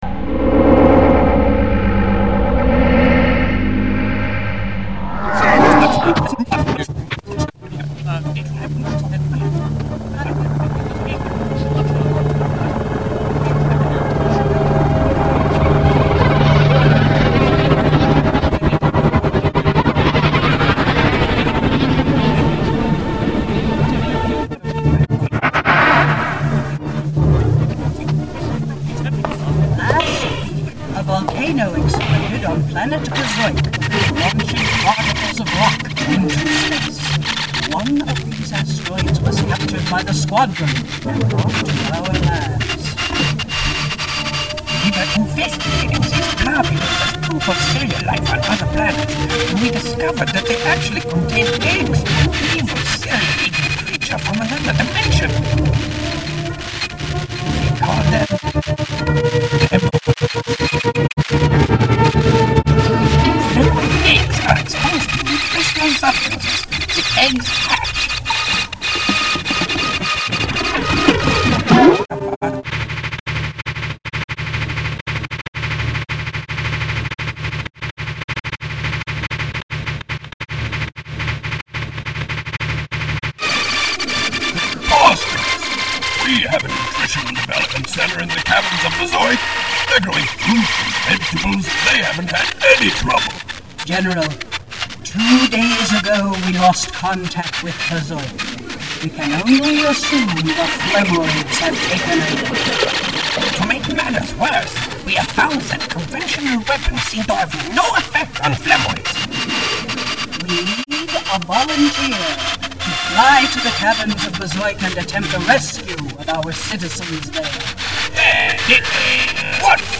Not really music, but experimental music.